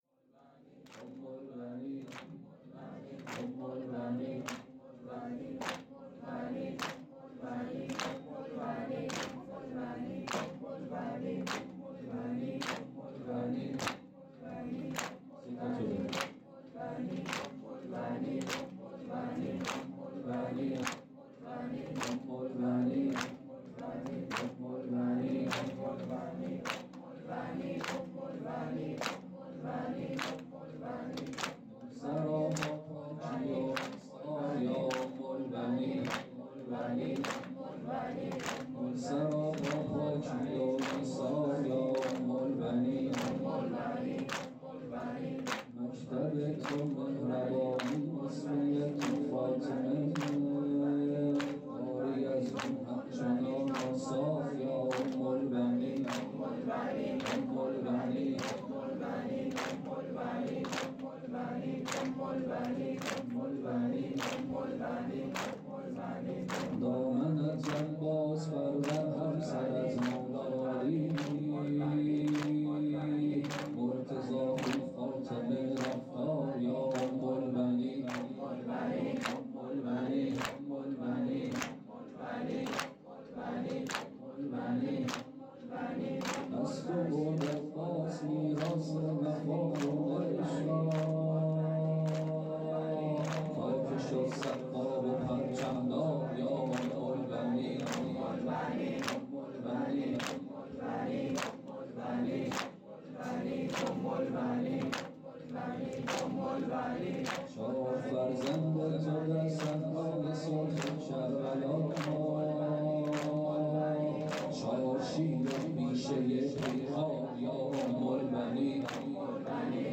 خیمه گاه - هیات انصارالحسین (ع) - زمینه - ای امیرالمومنین را یار یا ام البنین
هیات انصارالحسین (ع)